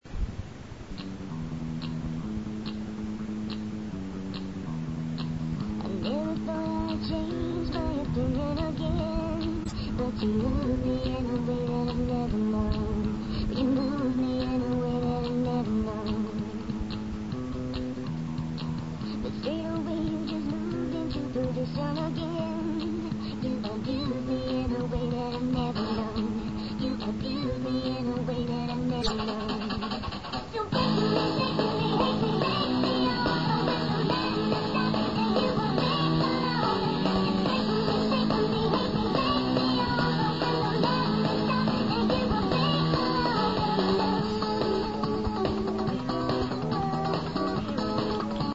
Know of a song you want to hear chipmunk-ized?